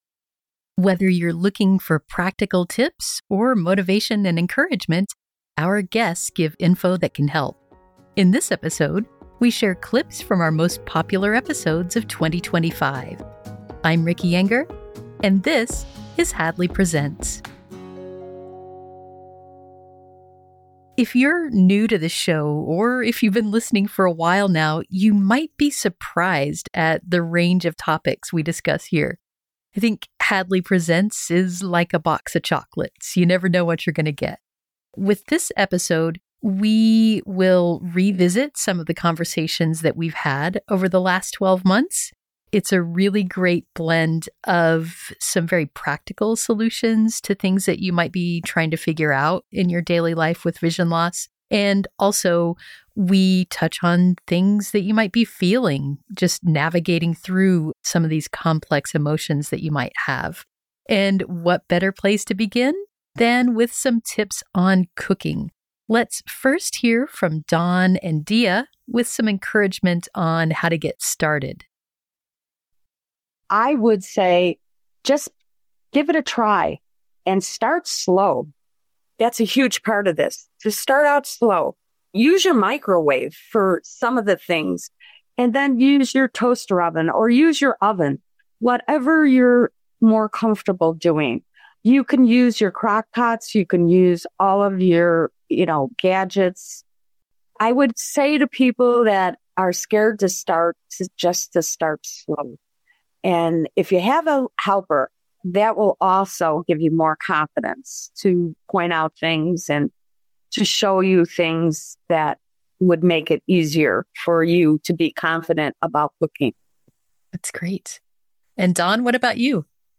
An episode of the Hadley Presents: A Conversation with the Experts audio podcast